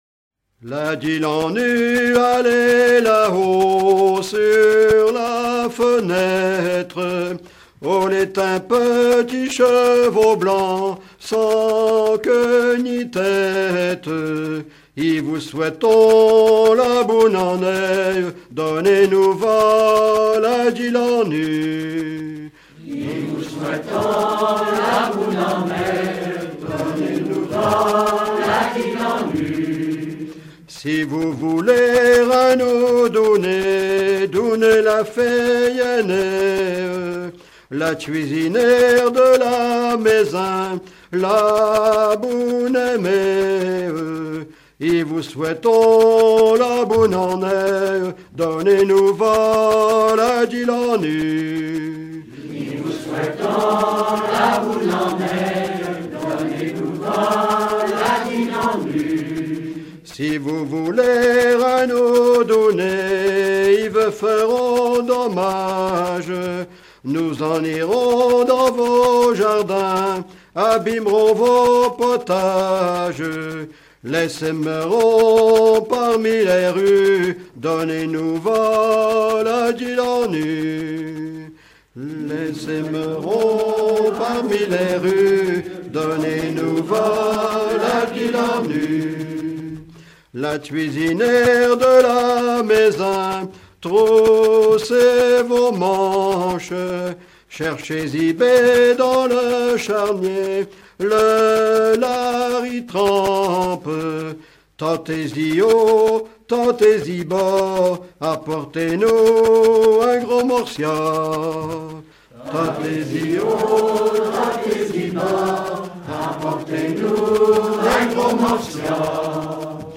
circonstance : quête calendaire
Genre strophique
Pièce musicale éditée